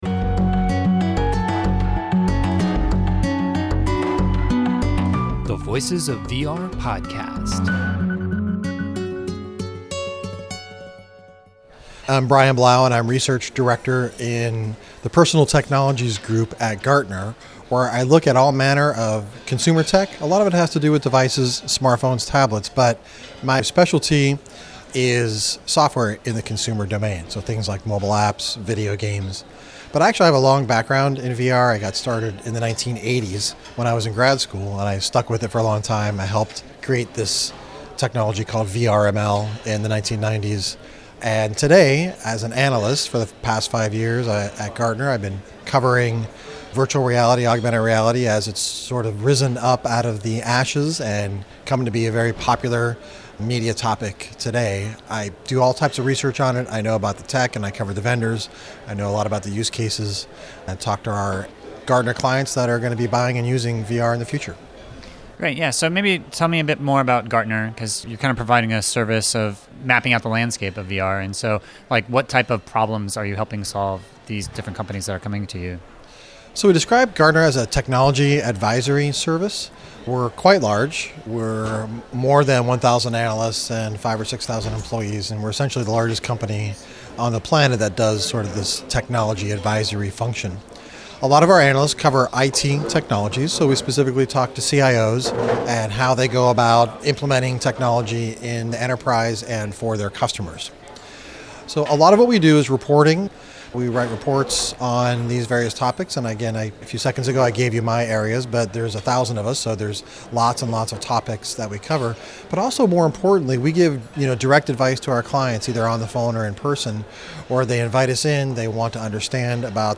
and so this was a really fascinating interview to get some insights about the significance of how VR and AR is going to change our relationship to technology and other people.